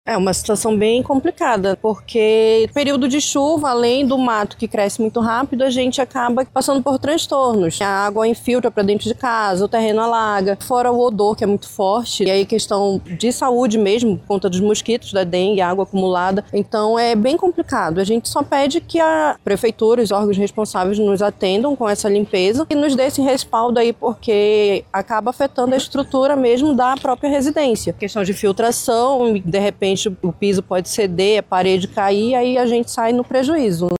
Os imóveis sofrem constantes alagamentos e despejo de resíduos fecais, principalmente, em dias chuvosos, conforme explica uma moradora da área.